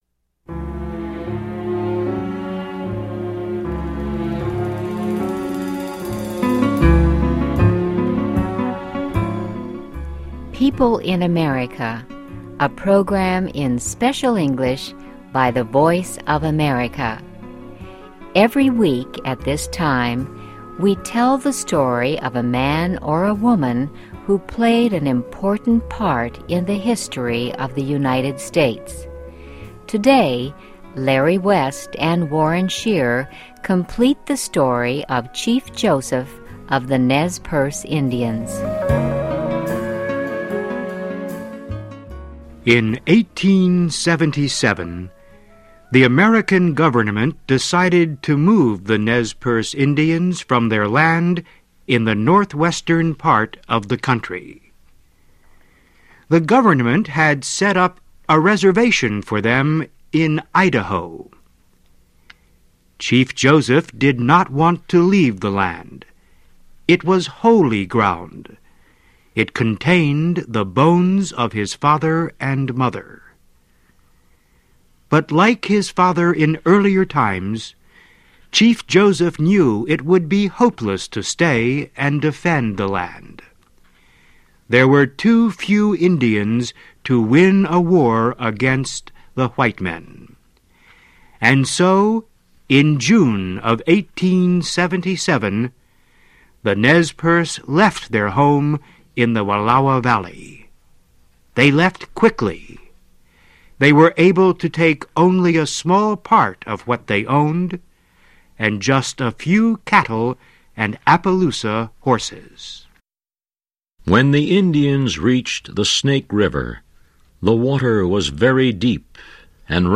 People in America, a program in Special English by the voice of America. Every week at this time, we tell the story of a man or a woman who played an important part in the history of the United States.